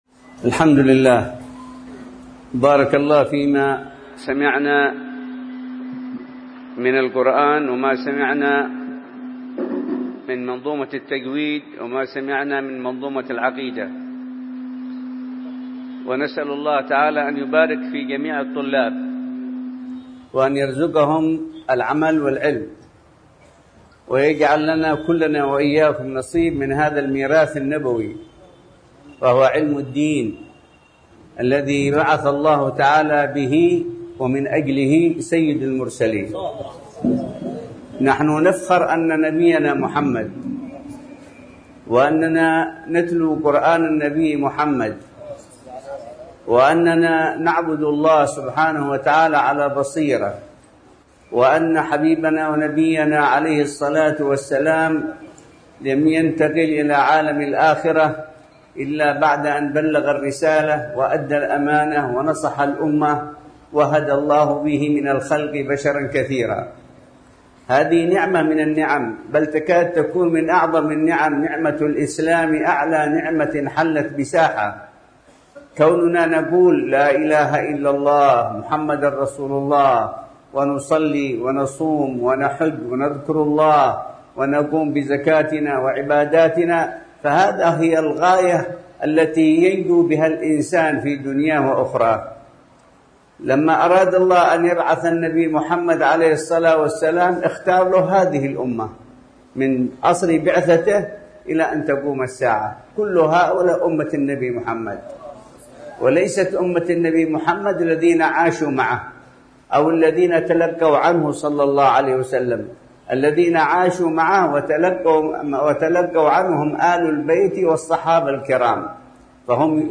محاضرة
بمسجد الإمام العيدروس بمدينة الشيخ عثمان بعدن